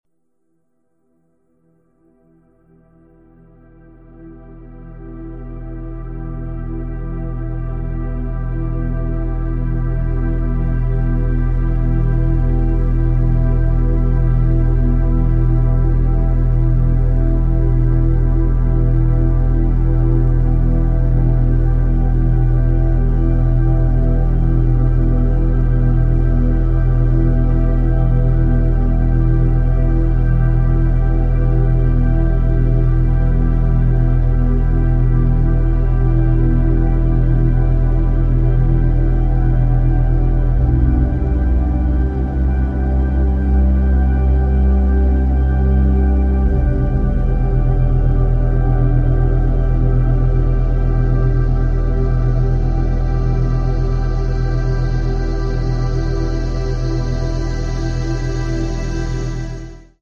Dieses Premium-Hörbuch enthält über 20 beruhigende Klangwelten für Babys in hervorragender Audioqualität.
• Sanftes braunes Rauschen;
• Herbstgeräusche (Wind & Blätter)
• Ozeanwellen (2 Varianten);
• Vorgeburtliche Geräusche im Mutterleib inkl. Herzschlag
• Grillenzirpen in einer lauen Sommernacht;
• Warmer Sommerregen;
• Lagerfeuerknistern;
• Sanftes Vogelgezwitscher;
• Gewitter mit Regenschauer;
**** HINWEIS: In der Hörprobe werden MEHRERE Klangwelten aus diesem Hörbuch für jeweils ca. 60 Sekunden angespielt. ****